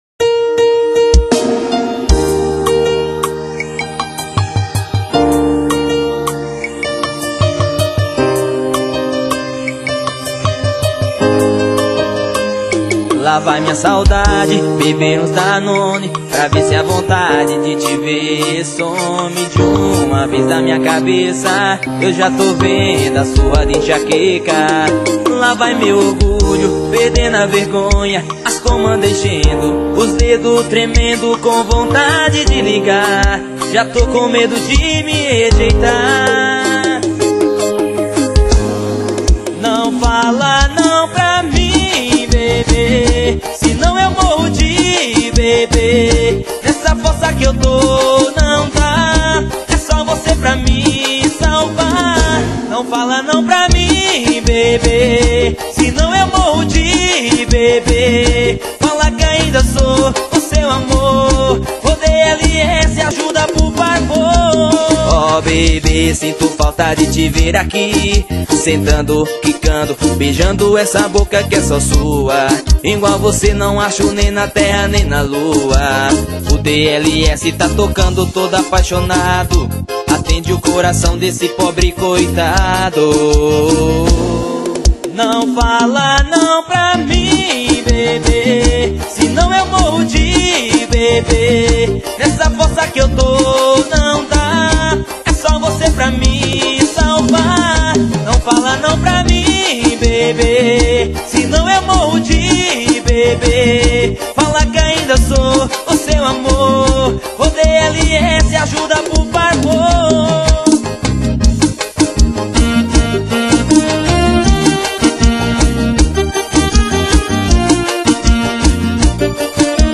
EstiloPiseiro